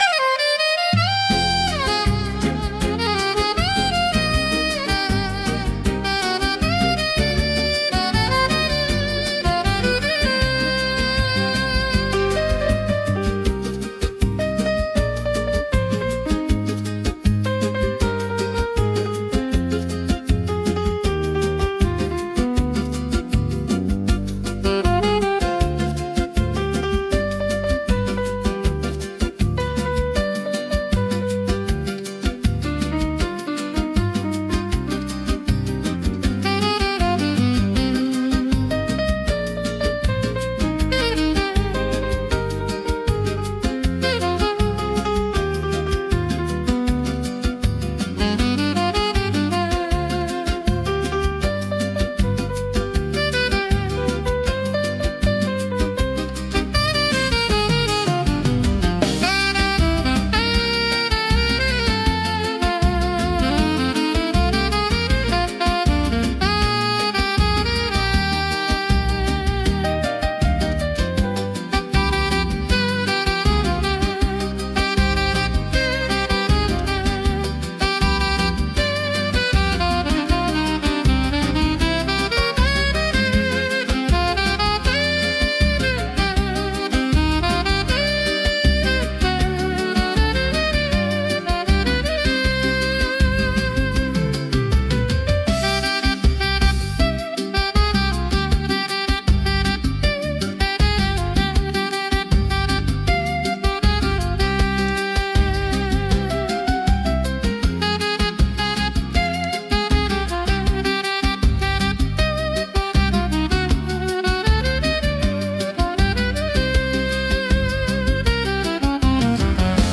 IA: música e arranjo) instrumental